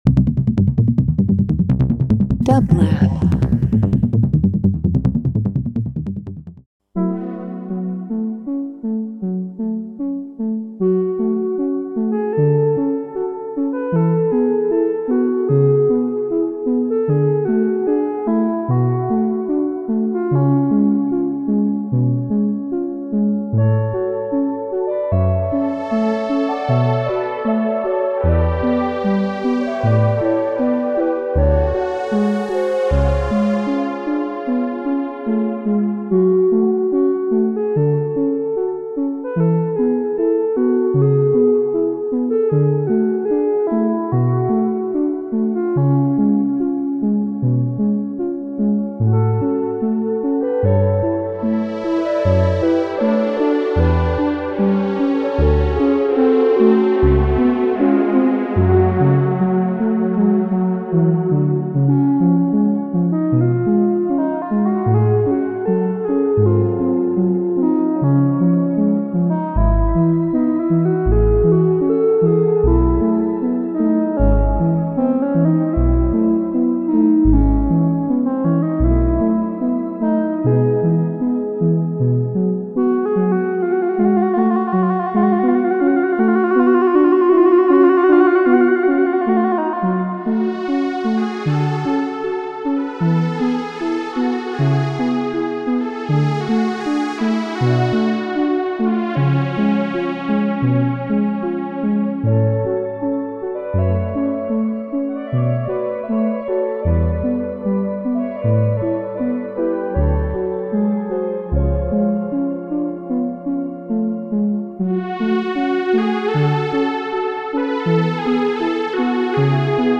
modular synthesizers
Electronic Experimental Live Performance Synth